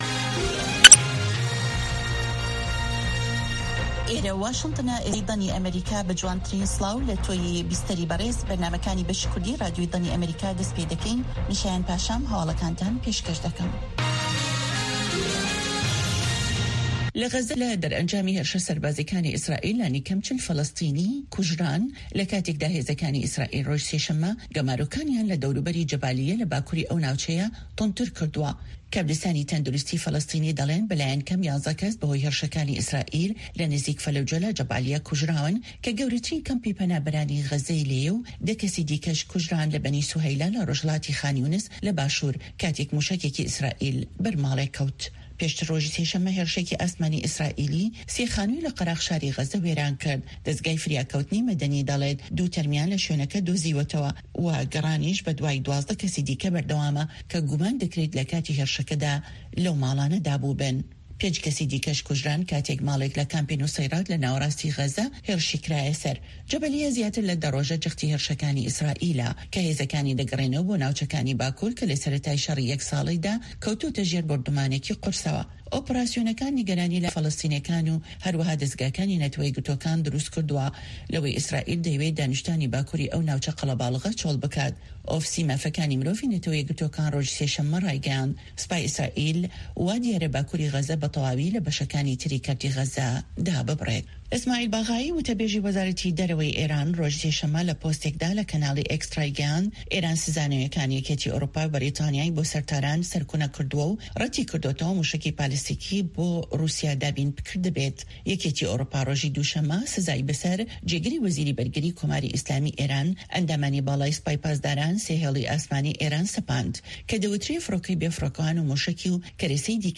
Nûçeyên 1’ê paşnîvro